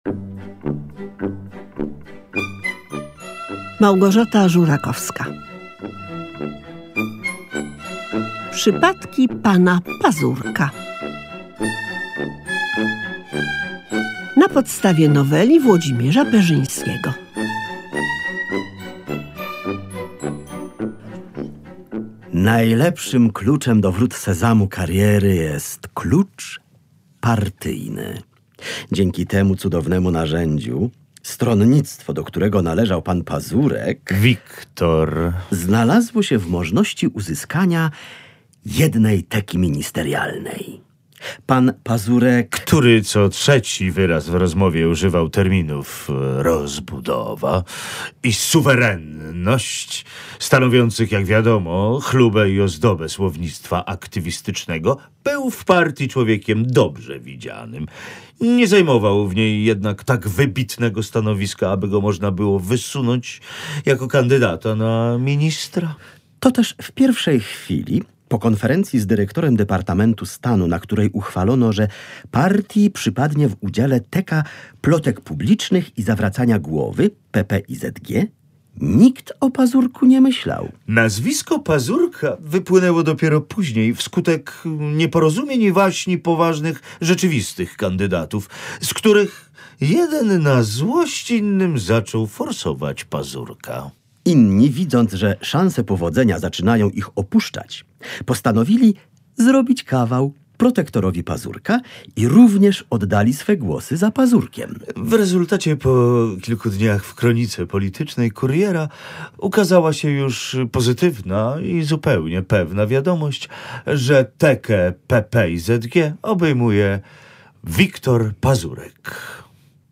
W programie słuchowisko oparte na opowiadaniach W. Perzyńskiego „Przypadki pana Pazurka”. Bohater zmierzy się z dziwną terminologią przestępstwa, kandydowaniem na ministra i niebiańskim Ministerstwem Aprowizacji Kłopotów…